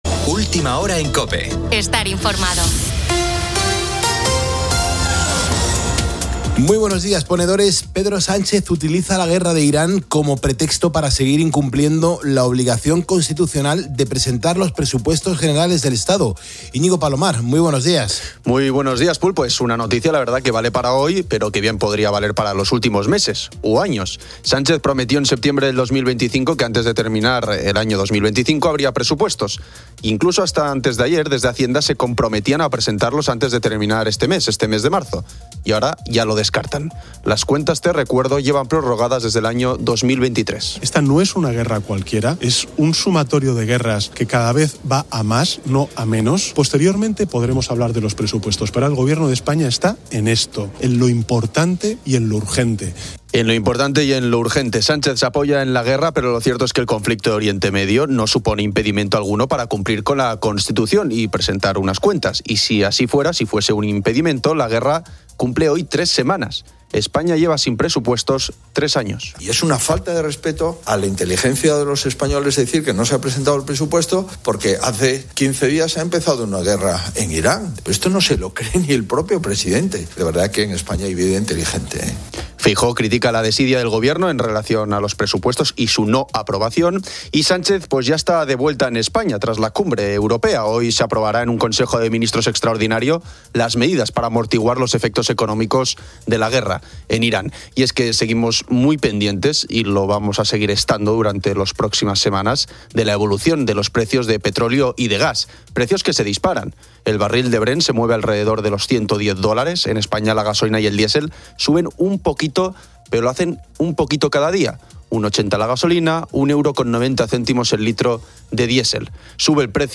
conversa con oyentes sobre la Semana Santa y entrevista a la cantante Sole Giménez